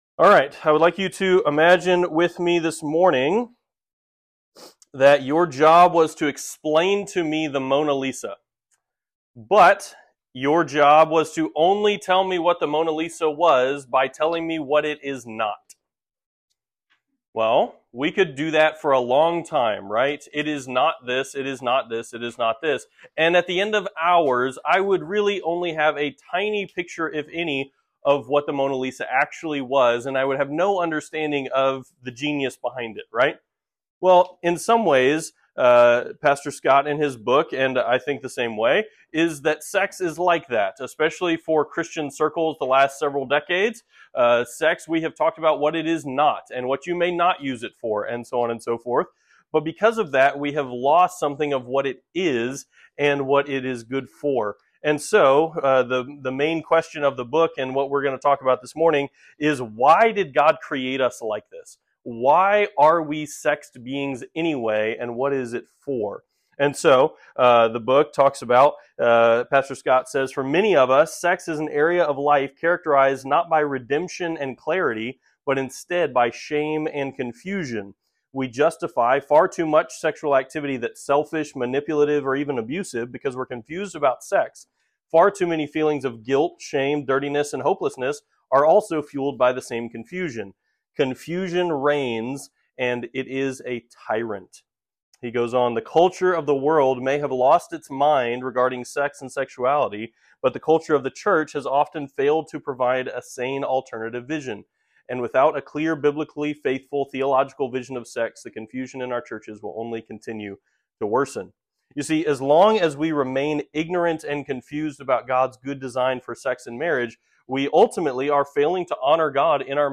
Beginnings Beginnings - Summer Seminar 2025 - Redeeming Sex in Marriage Audio Outline Series List Next ▶ Current 1.